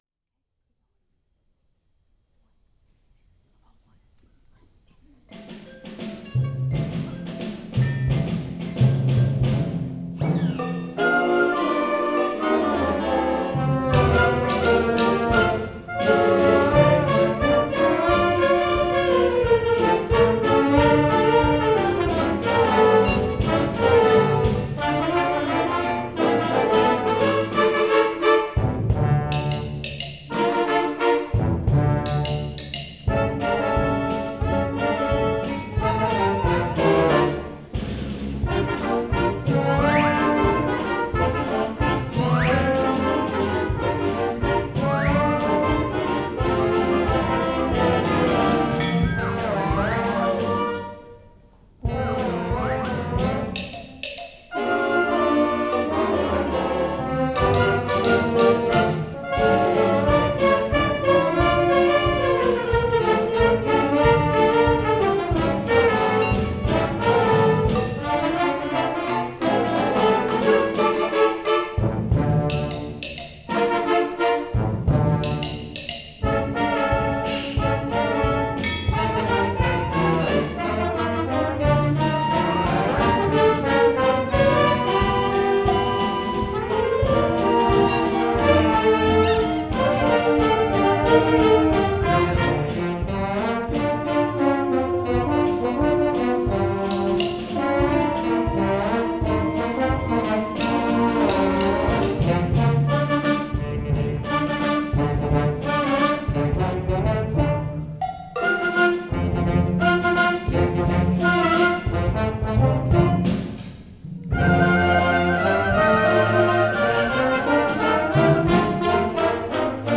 Alexander Bands — 2017 Spring Band Concert Symphonic Band